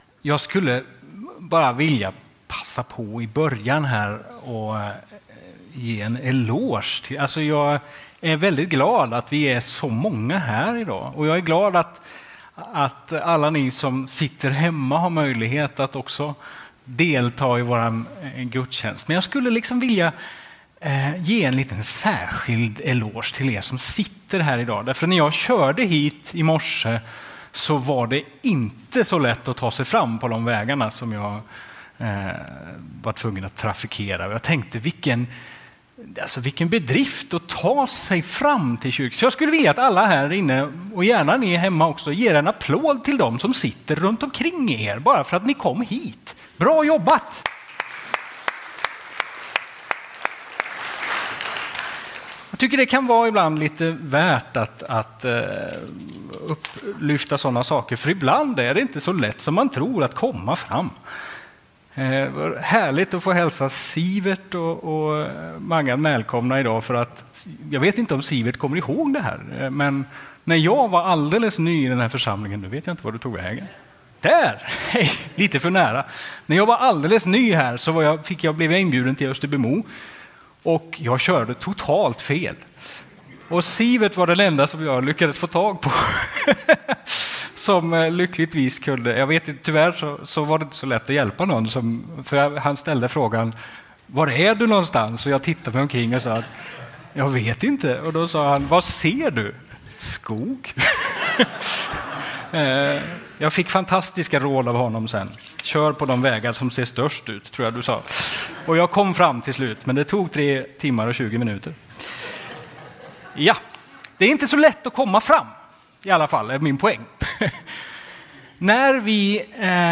Predikan har viss ankytning till dagens avsnitt i Adventsstudion.